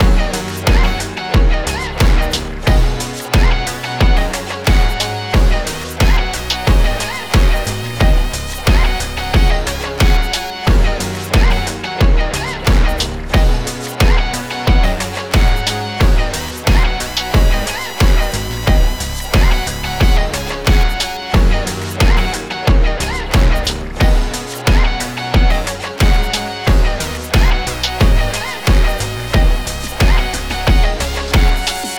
Gb Major
Eighties New
Game Voice
Music - Shred Guitar
Music - Power Chords
Music - Sitar
Long Saw
Trip